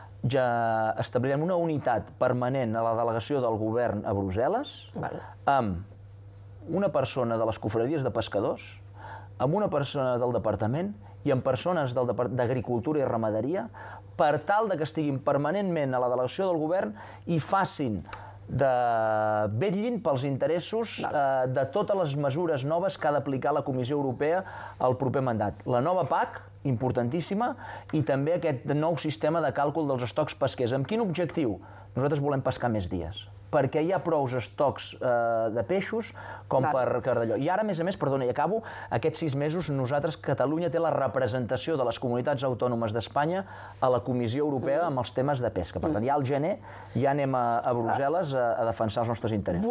Segons ha detallat aquest dimecres en una entrevista a el ‘Cafè d’idees’ de Ràdio 4 i La 2, actualment són al voltant de 130 dies, una xifra que considera no és viable per sostenir aquesta activitat.